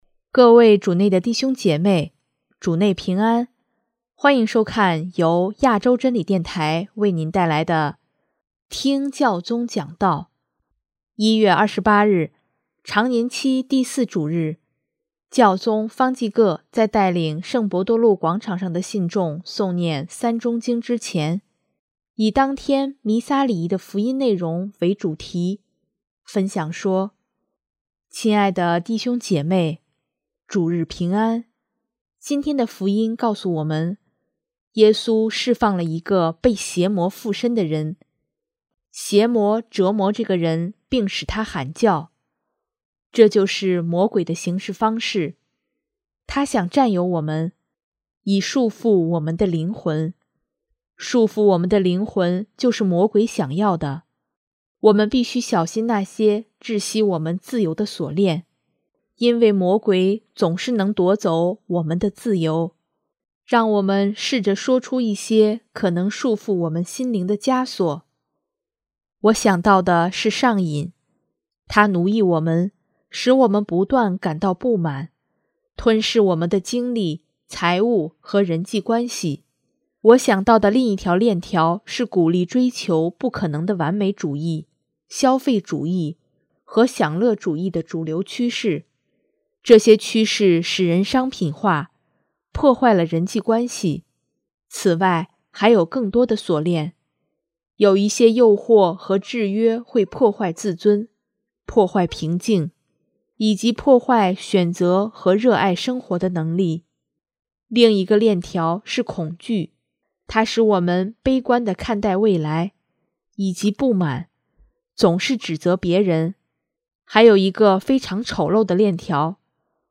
【听教宗讲道】|耶稣释放我们心灵的枷锁
1月28日，常年期第四主日，教宗方济各在带领圣伯多禄广场上的信众诵念《三钟经》之前，以当天弥撒礼仪的福音内容为主题，分享说：